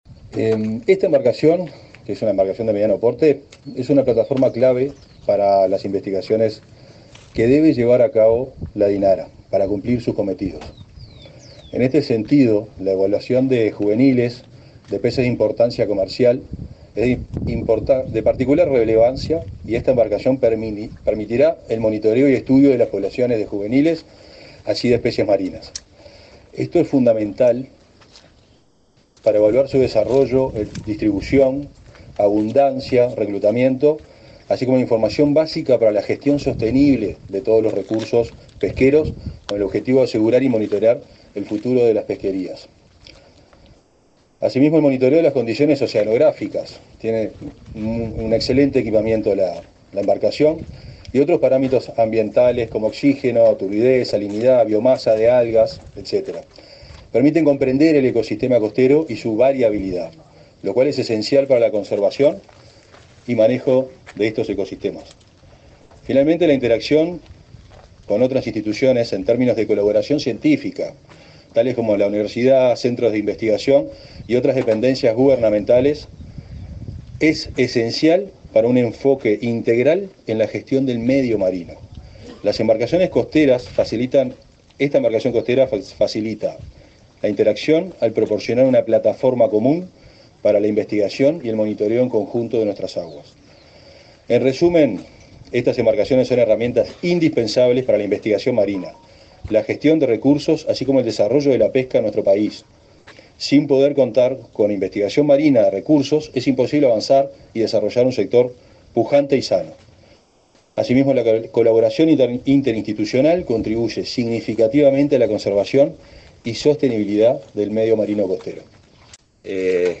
Palabras de autoridades del MGAP
El director de la citada dependencia, Álvaro Irazoqui, y el titular del Ministerio de Ganadería, Agricultura y Pesca (MGA), Fernando Mattos, participaron en el acto, realizado este martes 29 en el barrio Cerro, de Montevideo.